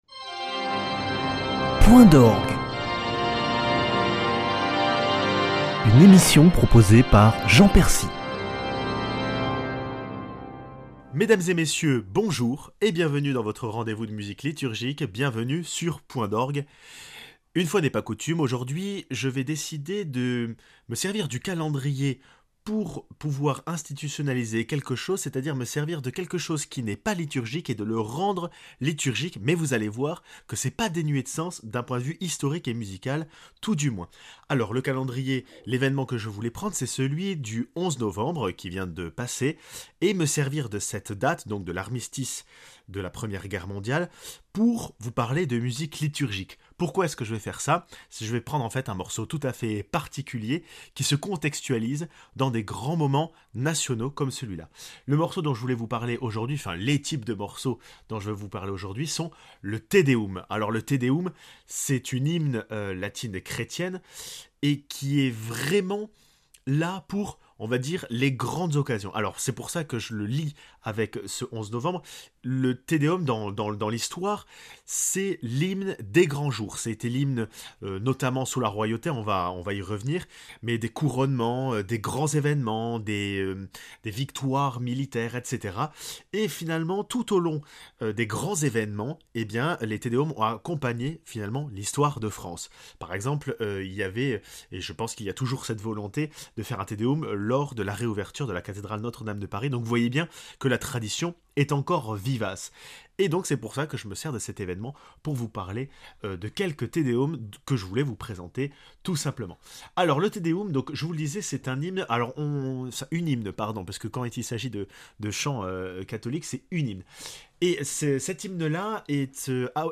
Fréquemment chanté lors des grands événements de l'Histoire de France, voici un petit florilège de ces œuvres grandioses.